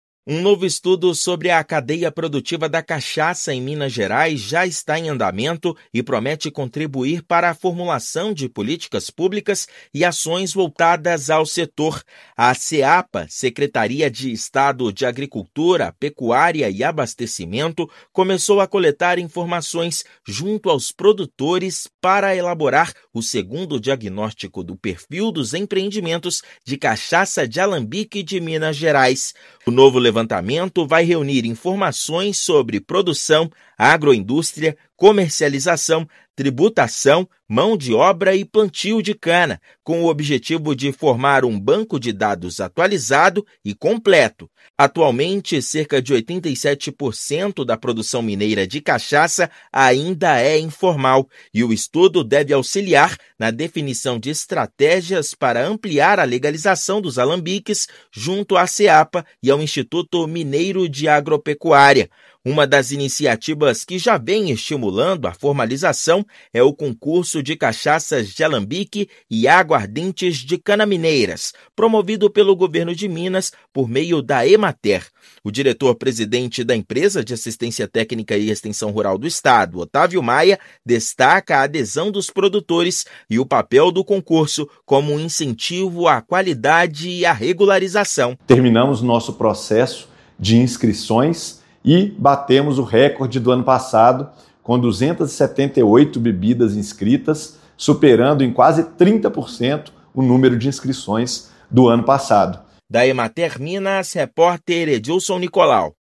[RÁDIO] Secretaria de Agricultura inicia coleta de dados para o Anuário 2025 da cachaça
Qualquer pessoa pode participar, respondendo à questionário disponibilizado no site da Seapa até 10/10. Ouça matéria de rádio.